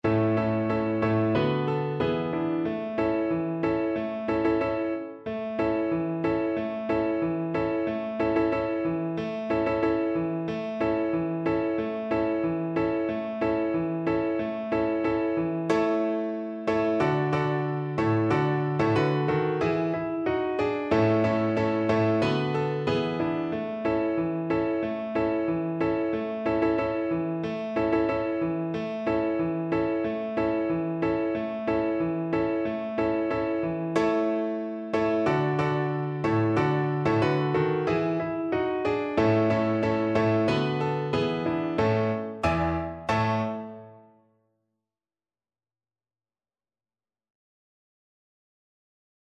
2/2 (View more 2/2 Music)
Happily =c.92
Classical (View more Classical Violin Music)